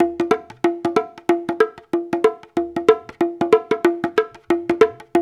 93 -UDU B05.wav